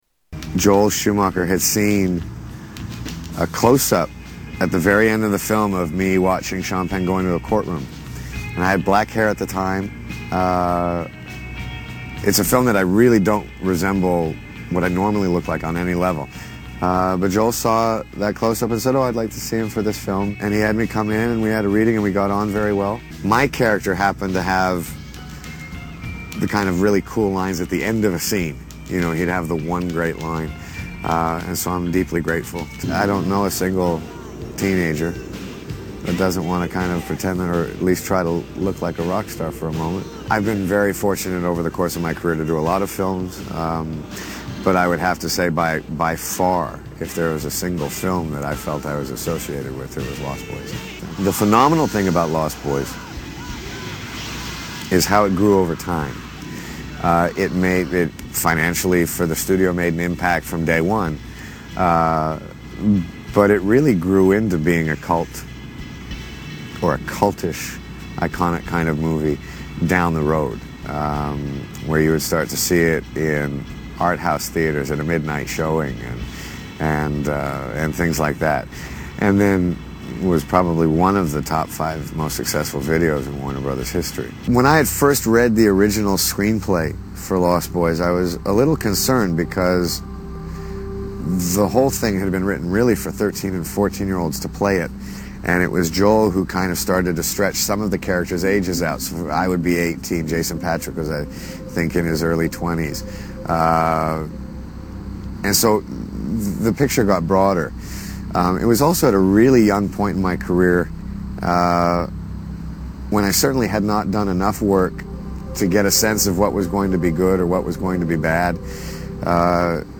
Kiefer Sutherland Interview